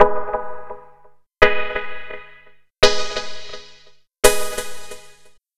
07 Jah Filter 170 B.wav